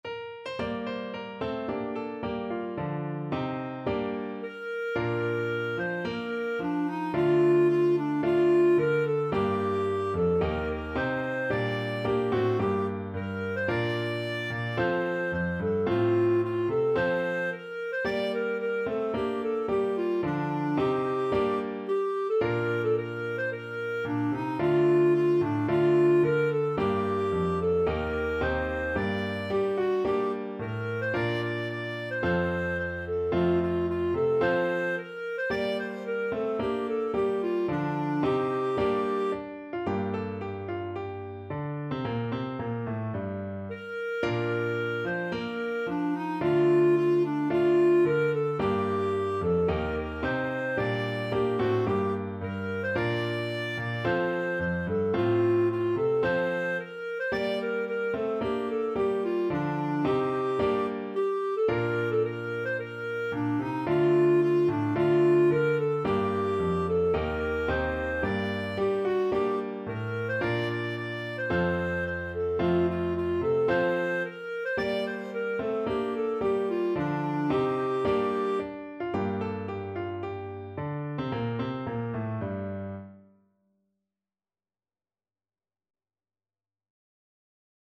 4/4 (View more 4/4 Music)
~ = 110 Allegro (View more music marked Allegro)